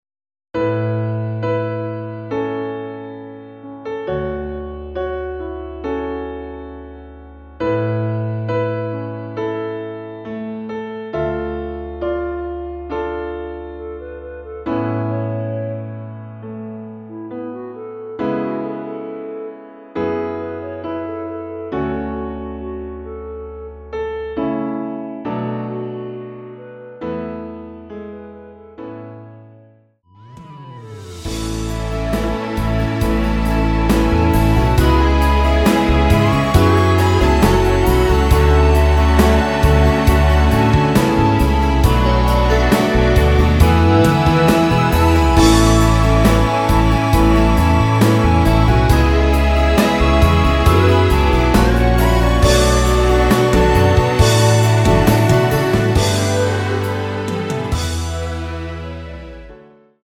(여자키) 멜로디 포함된 MR(미리듣기 참조)
앞부분30초, 뒷부분30초씩 편집해서 올려 드리고 있습니다.
중간에 음이 끈어지고 다시 나오는 이유는